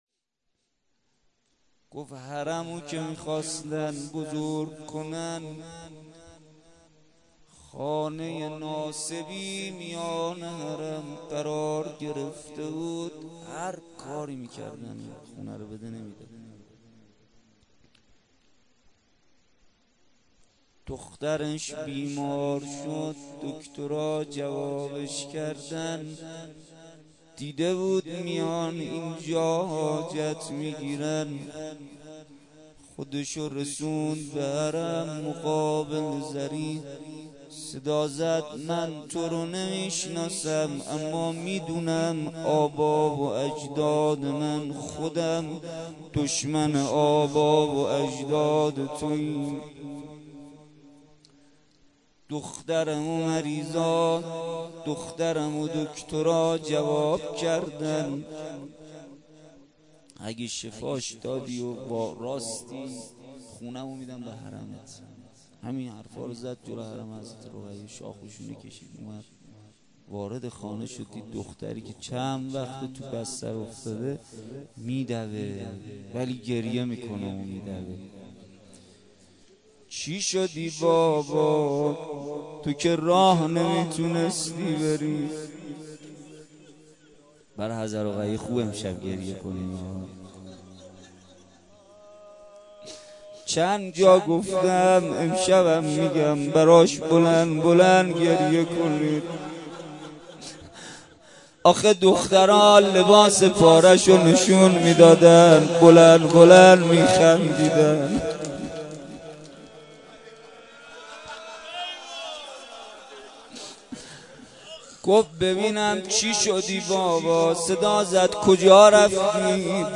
مداحی
در ولنجک – بلوار دانشجو – کهف الشهداء برگزار گردید.
مناجات (دعای افتتاح) روضه حضرت رقیه سلام الله علیها لینک کپی شد گزارش خطا پسندها 0 اشتراک گذاری فیسبوک سروش واتس‌اپ لینکدین توییتر تلگرام اشتراک گذاری فیسبوک سروش واتس‌اپ لینکدین توییتر تلگرام